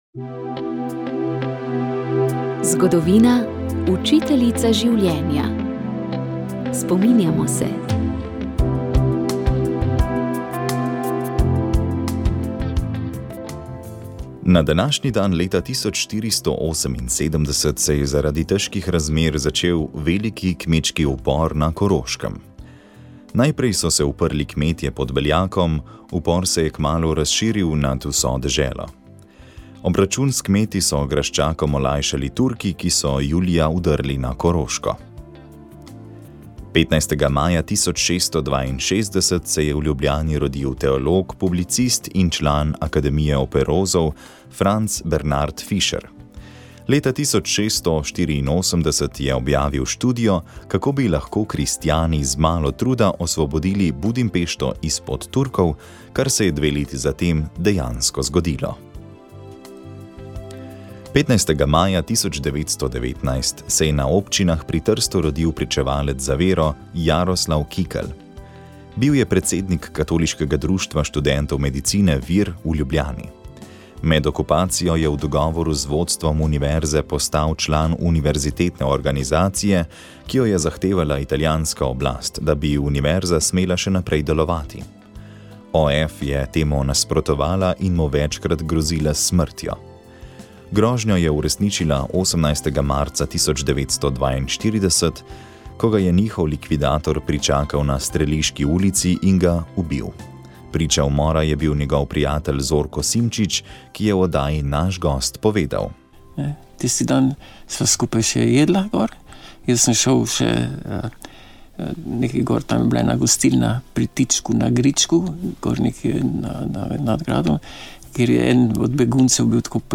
Radijska kateheza
Nadškof Anton Stres je odgovarjal na vprašanja kako je človek razmišljal o sebi in presežnem skozi čas, kdaj se je pojavil ateizem v širšem obsegu in kakšno vlogo ima na področju verovanja, umetnost?